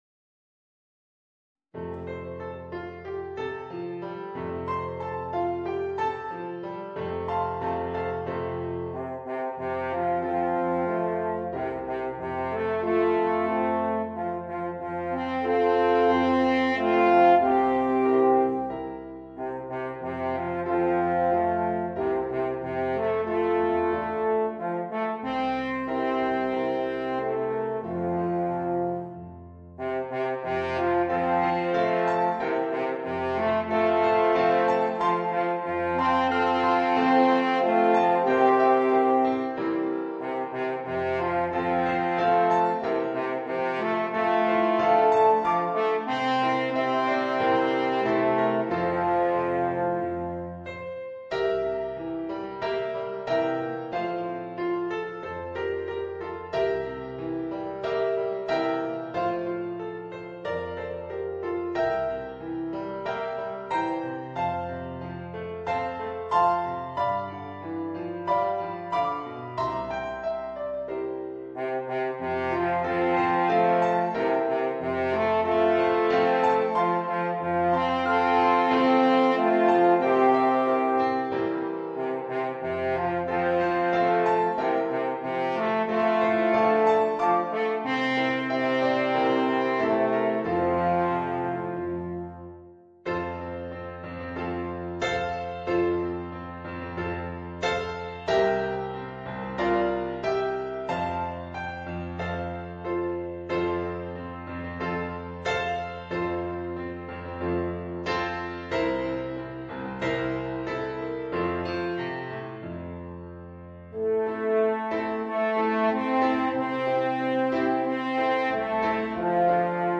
Voicing: 2 Alphorns